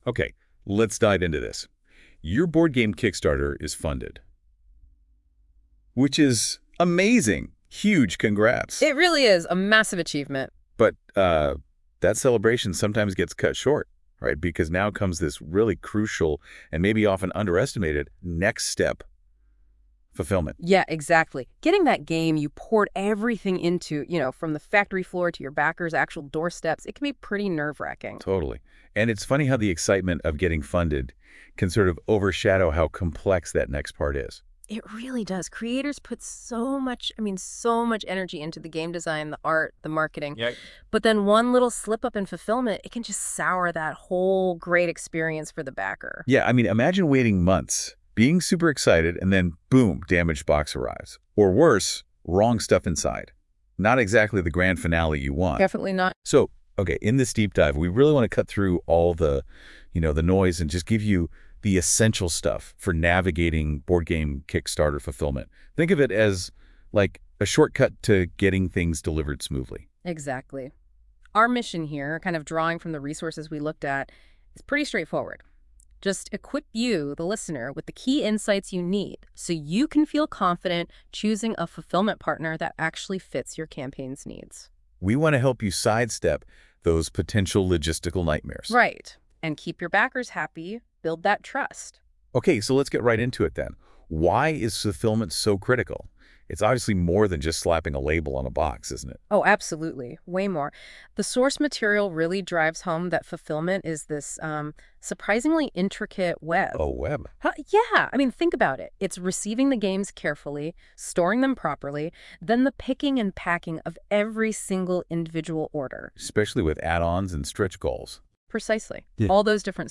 by eFS & NotebookLM